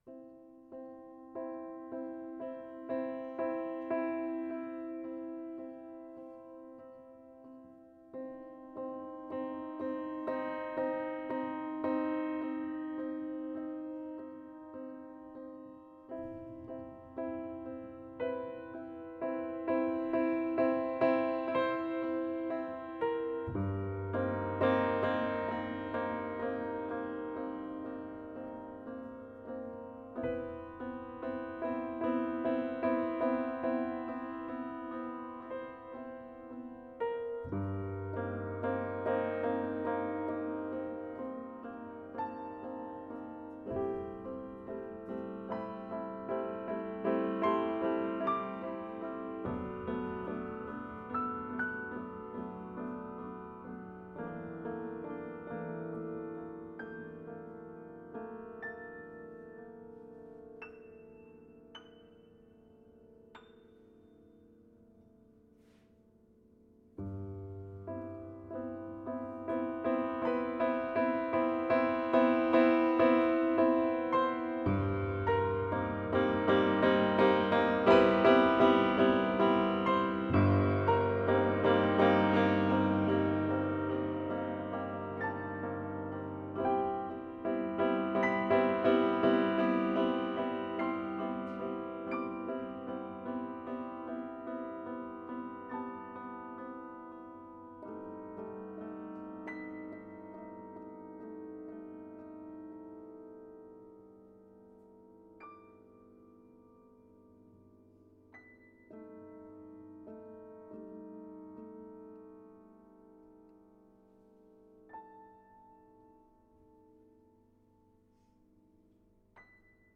Benefit Concert
New School of Music, 25 Lowell St, Cambridge [directions]